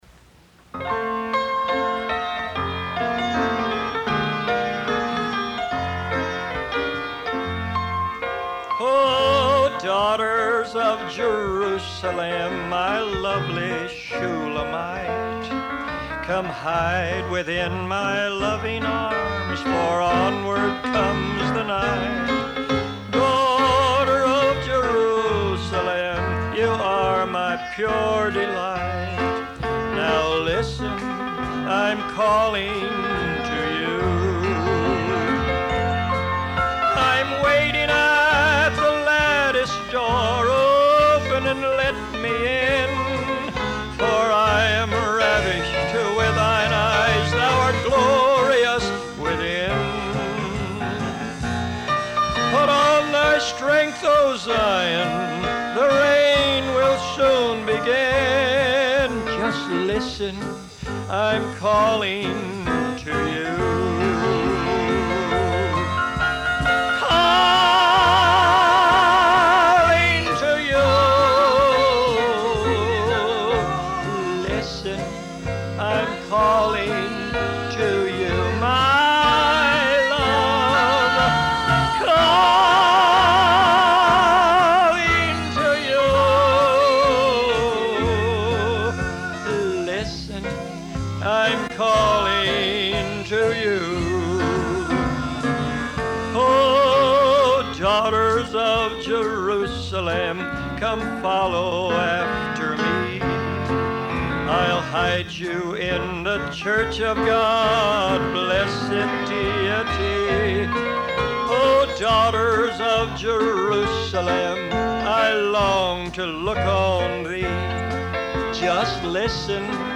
They sing old melodies that are dear to our hearts.
beautiful tenor voice
beautiful vocal harmony
The date and location of this amateur recorded session is unclear. What is certain, is that this was not an attempt to be professional, but rather a preserving of memories.
is playing the piano